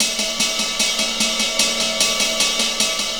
Ride 01.wav